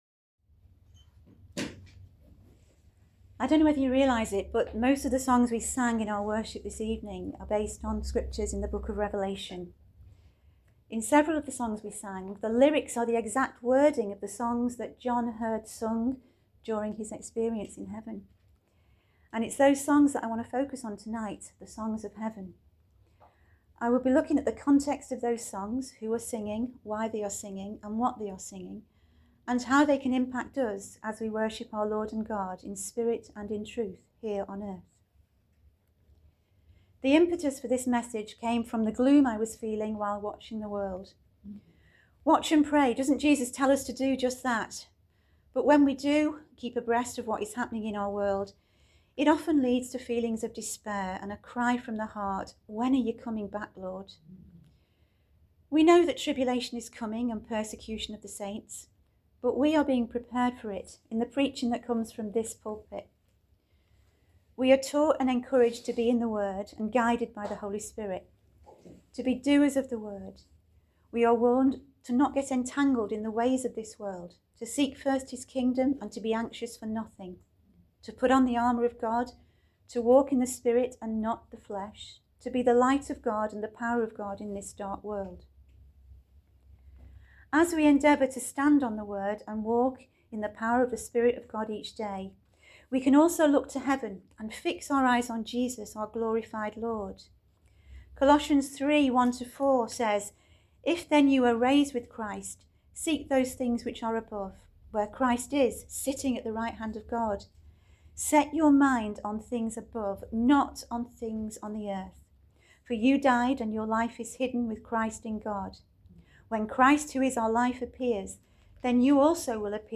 Ladies message